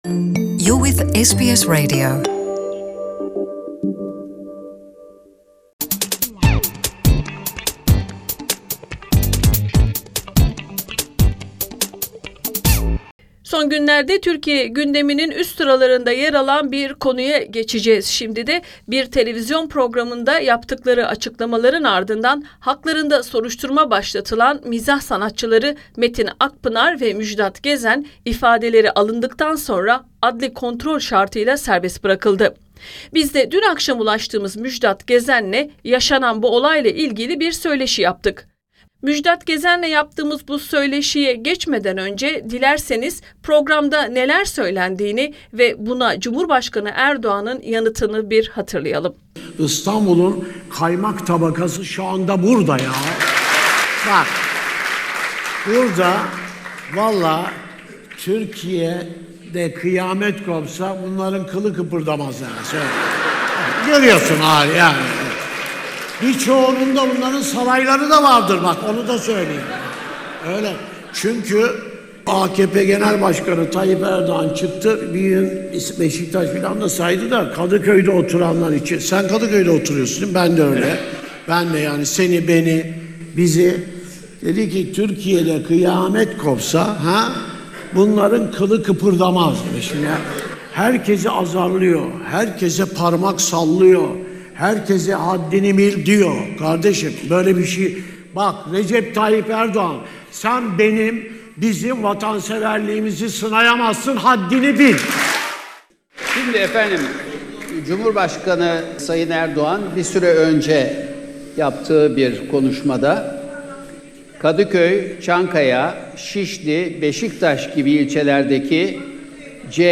Katıldıkları bir televizyon programındaki sözleri nedeniyle Cumhurbaşkanı Erdoğan'ın sert eleştirilerine maruz kalan ünlü komedi sanatçıları Metin Akpınar ve Müjdat Gezen, haklarında başlatılan soruşturma kapsamında gittikleri adliyede ifadeleri alındıktan sonra adli kontrol şartıyla serbest bırakıldı. SBS Türkçe'ye konuşan Müjdat Gezen Cumhurbaşkanı Erdoğan'ın iddalarını reddederek, amacın kendilerini sindirmek olduğunu kaydetti.